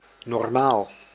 Normaal (Dutch pronunciation: [nɔrˈmaːl]
Nl-normaal.ogg.mp3